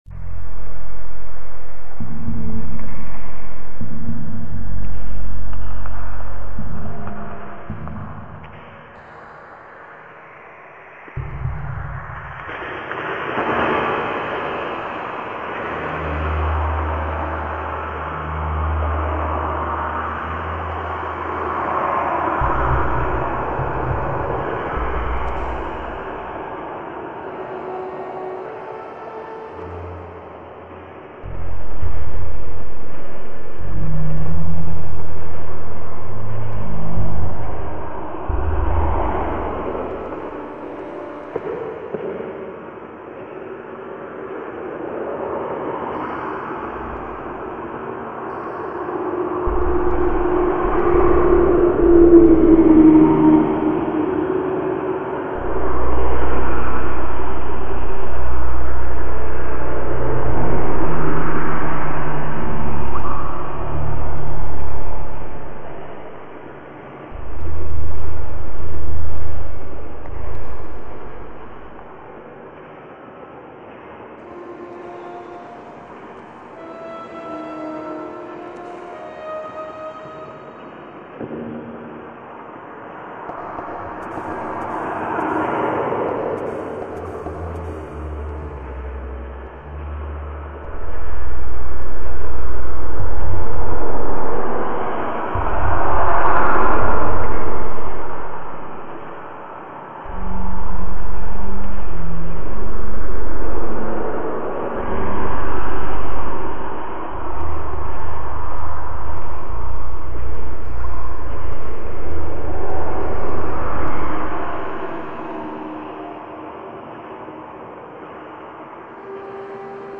• Жанр: Электронная
городской эмбиент